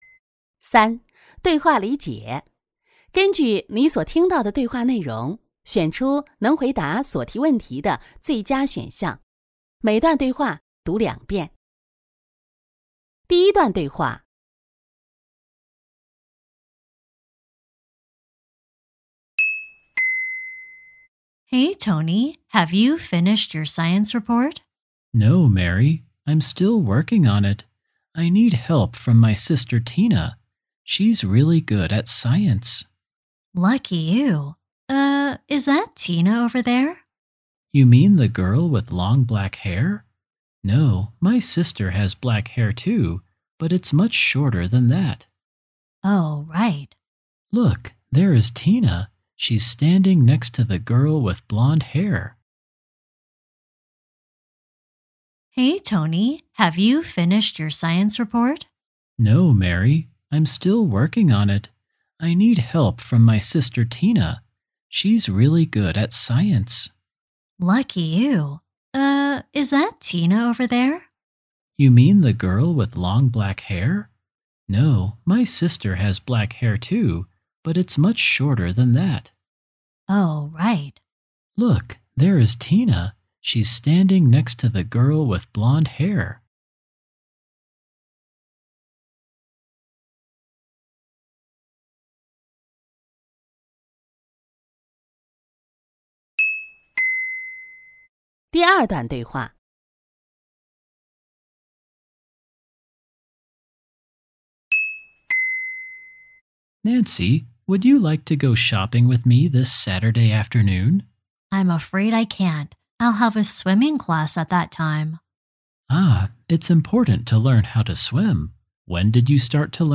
[2021海南卷]对话理解